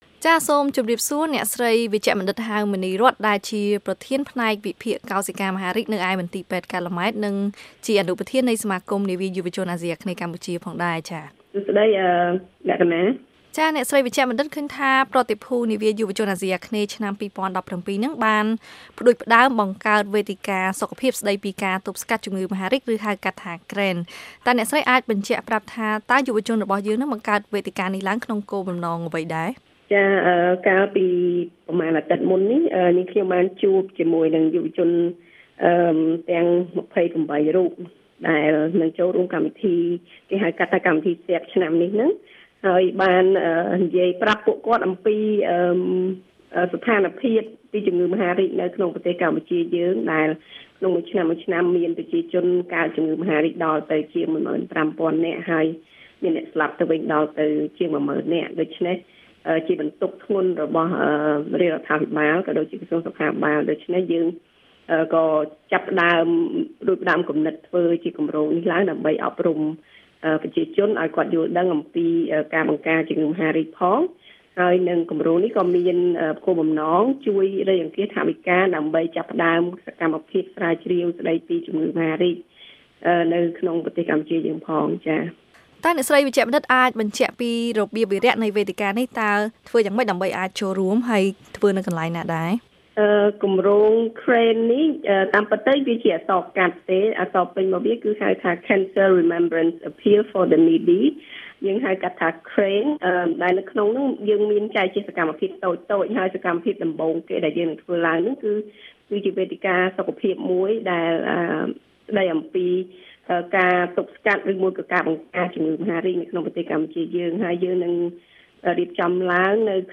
បទសម្ភាសន៍ VOA៖ វេទិកាសុខភាពស្តីពីការបង្ការជំងឺមហារីក